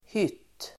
Uttal: [hyt:]